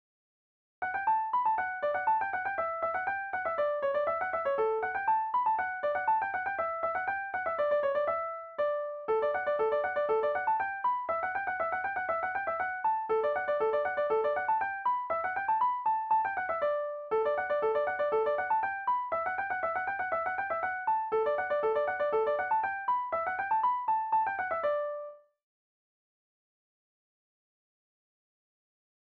Fonction d'après l'analyste danse : branle : avant-deux ;
Enquête EthnoDoc et Arexcpo dans le cadre des activités courantes des membres des associations